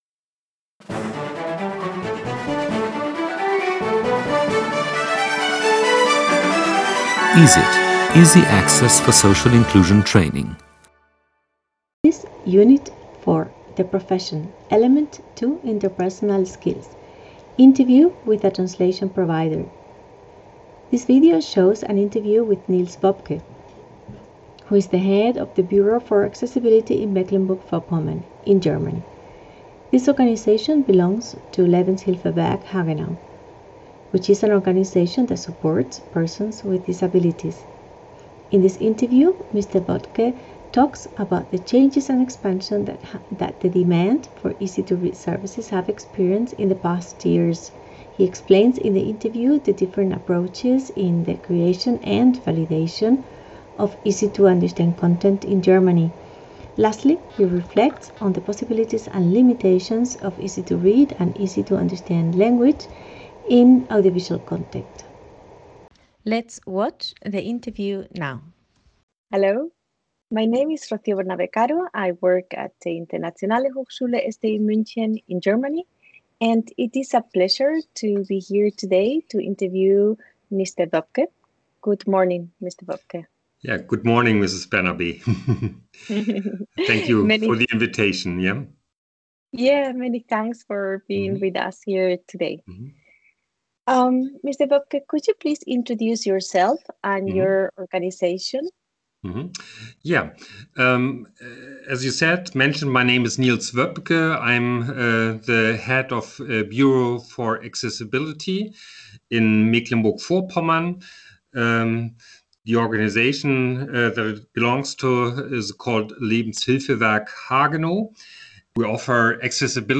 4.2.3. Interview with a translation provider